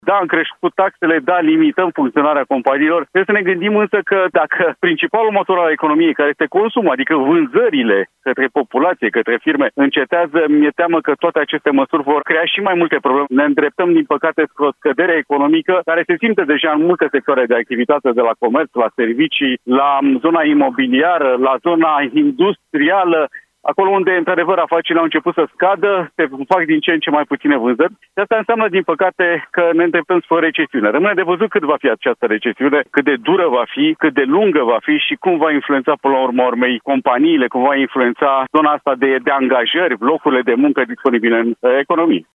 analist economic: „Ne îndreptăm, din păcate, spre o scădere economică care se simte deja în multe sectoare de activitate”